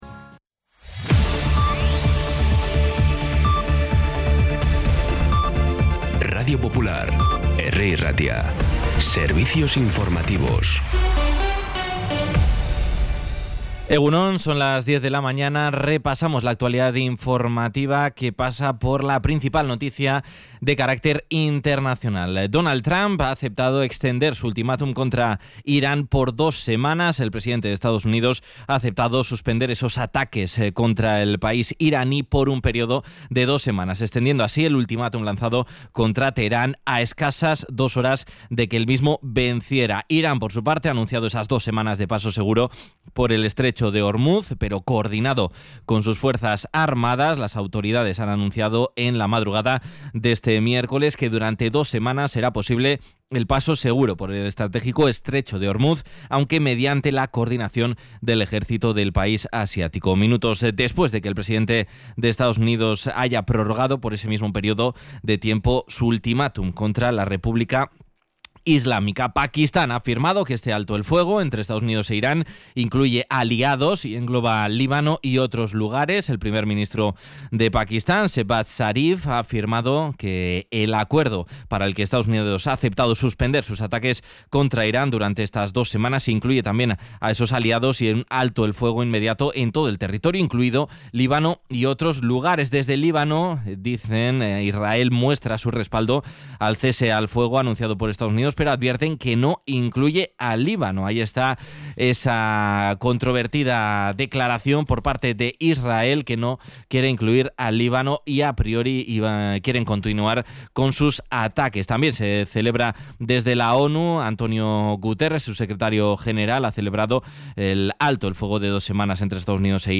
Las noticias de Bilbao y Bizkaia del 8 de abril a las 10
Los titulares actualizados con las voces del día. Bilbao, Bizkaia, comarcas, política, sociedad, cultura, sucesos, información de servicio público.